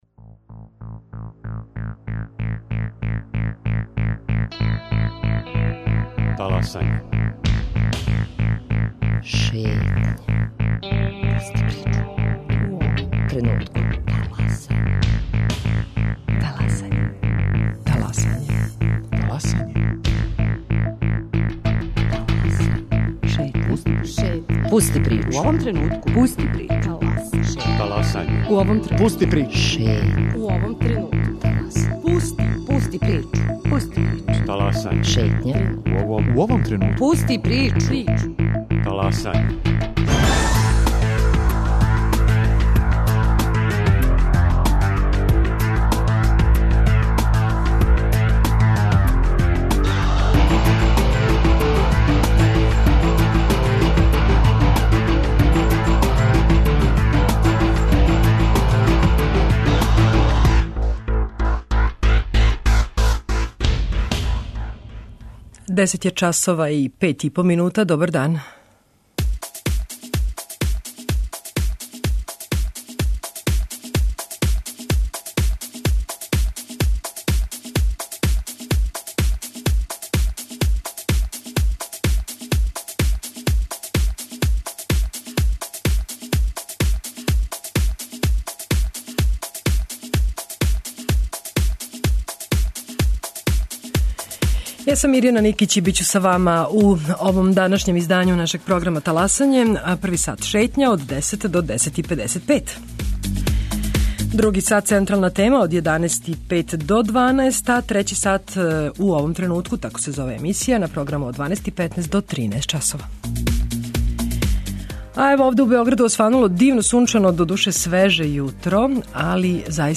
Гошће Шетње су апсолвенткиње Факултета политичких наука, тренутно на пракси у Радио Београду.